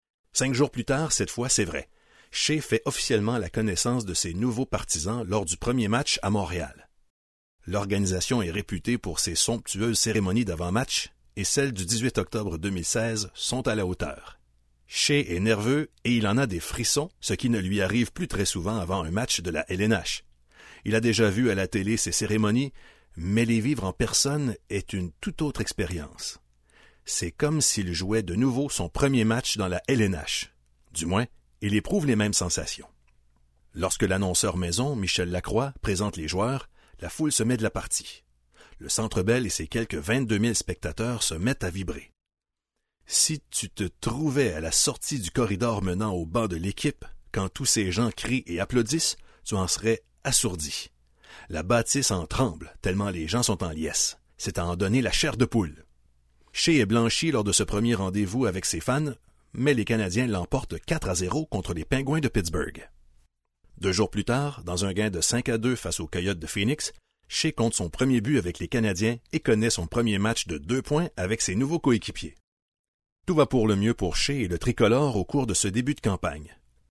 warm, authoritative French Canadian voice over with bass resonance
Audiobooks
All this in a well-soundproofed cedar wardrobe that smells good!
Bass